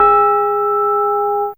ChimesG2G2.wav